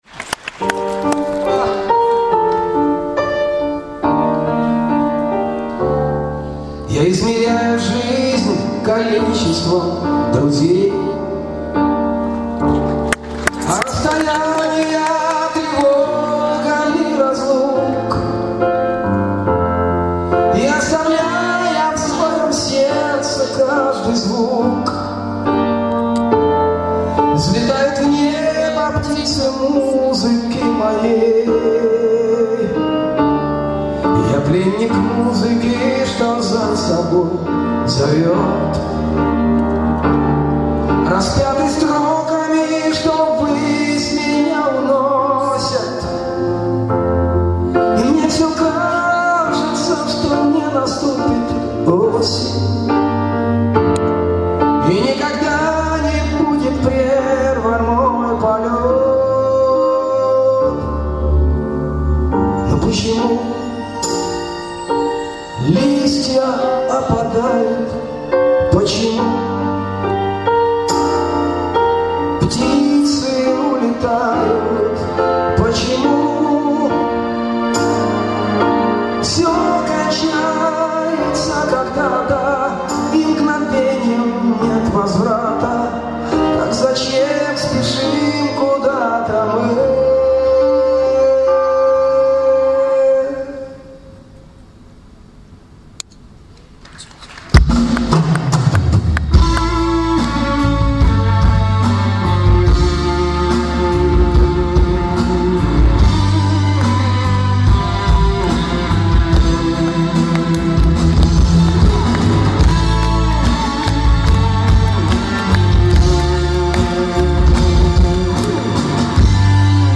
24 февраля 2008 года, Москва, Кремль.